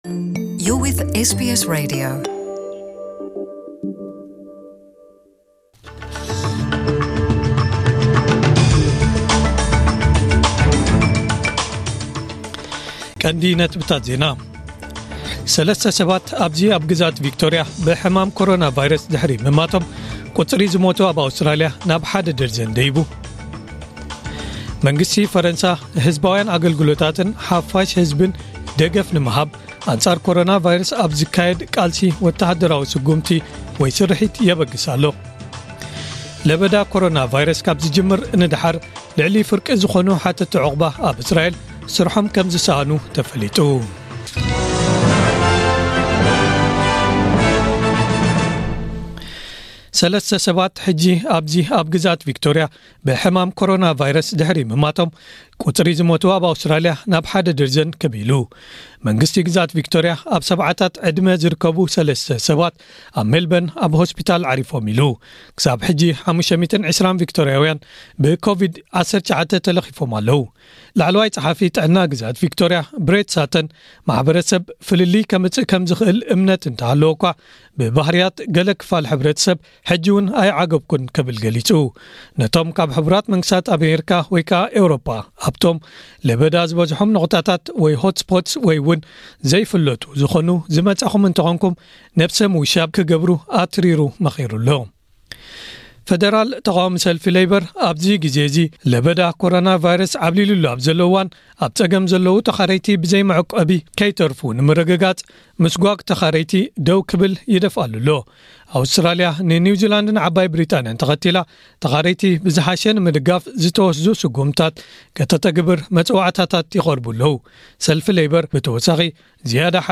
ዕለታዊ ዜና